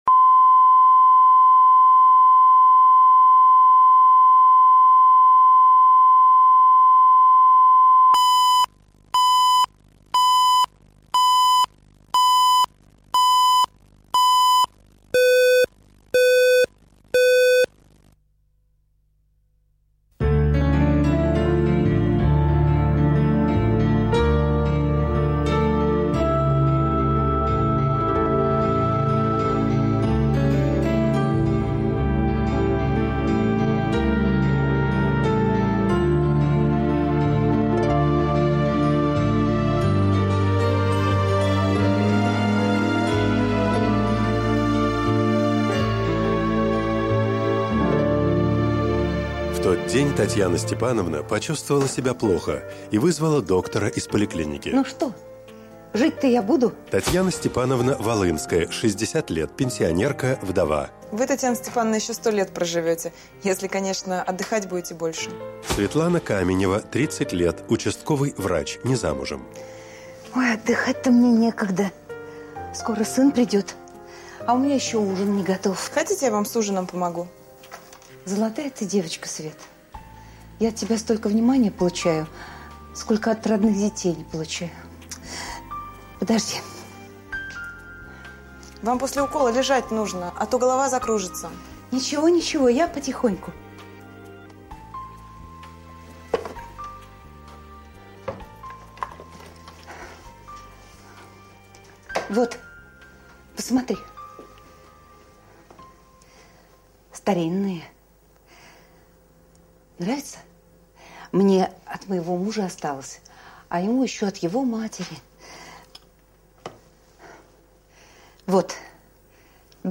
Аудиокнига Дорогой мой человек | Библиотека аудиокниг